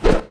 NutThrow.wav